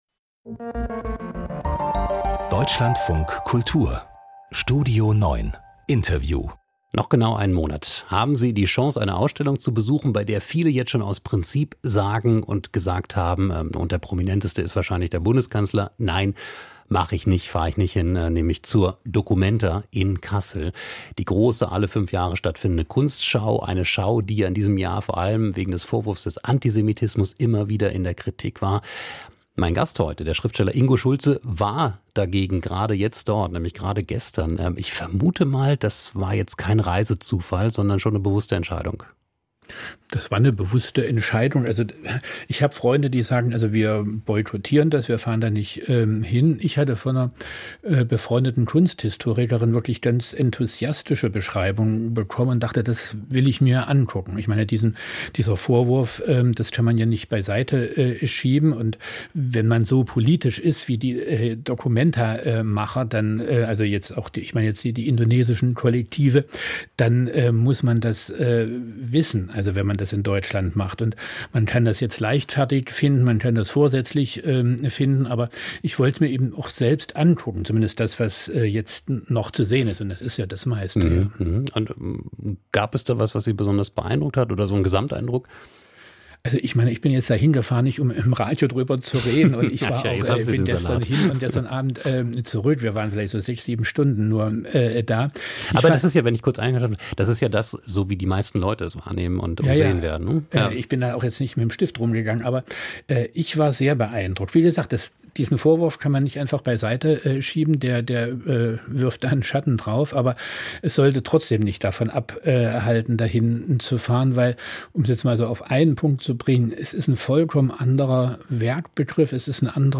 Die Themen des Tages mit einem Gast aus Medien, Kultur oder Politik: Das ist die Mischung von... Mehr anzeigen